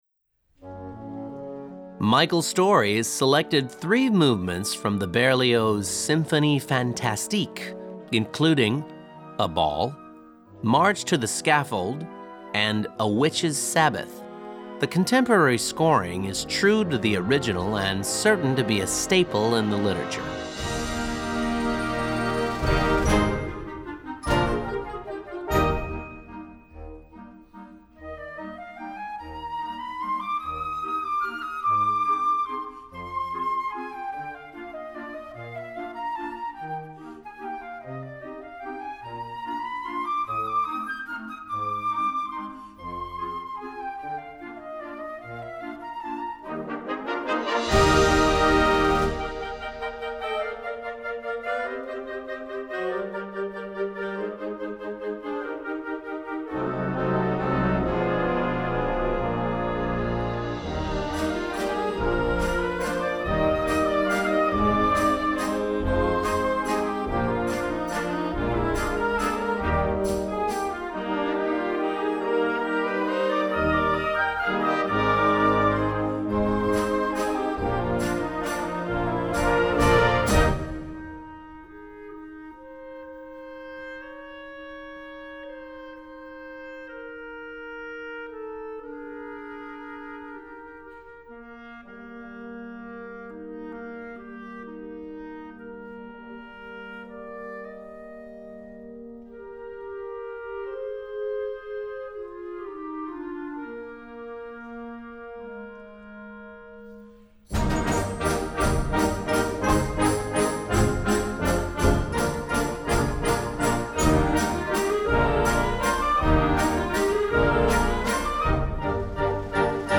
Gattung: Suite
Besetzung: Blasorchester
Carefully scored for the contemporary concert band.